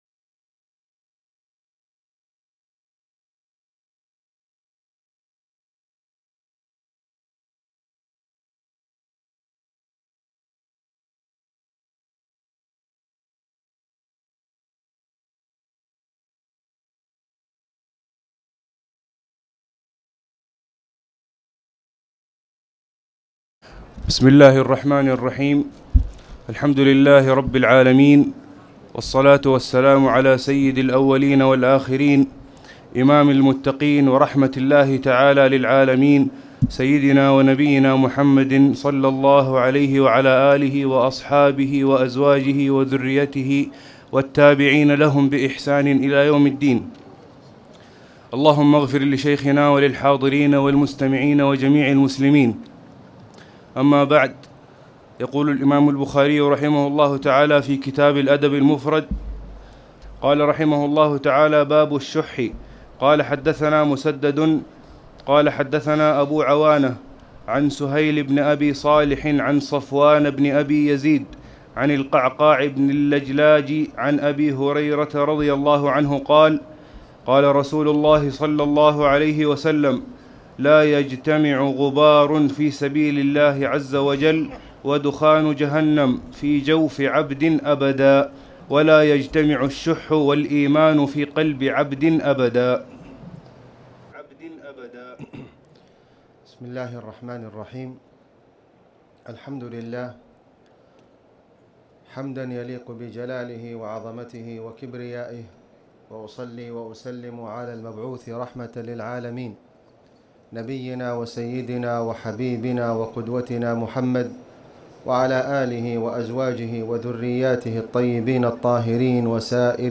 تاريخ النشر ٢٠ رجب ١٤٣٨ هـ المكان: المسجد الحرام الشيخ: خالد بن علي الغامدي خالد بن علي الغامدي باب الشح